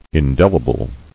[in·del·i·ble]